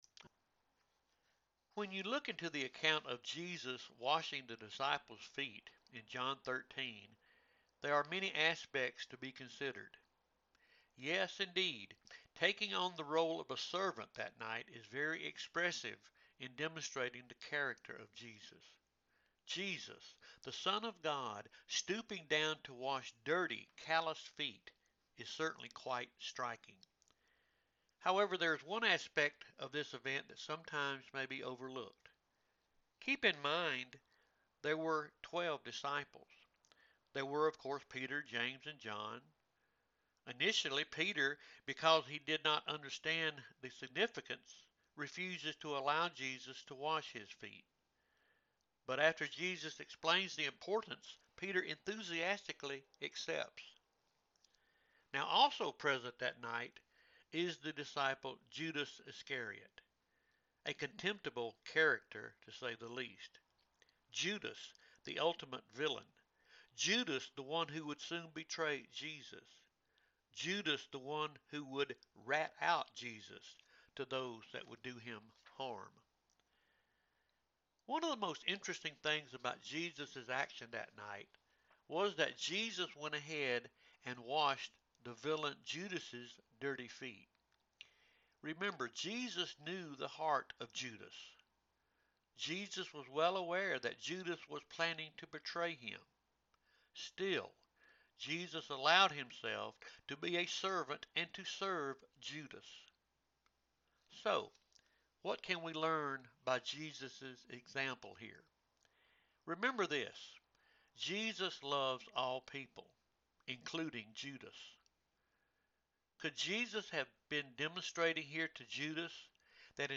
Tuesday Morning Devotion – Lyerly United Methodist Church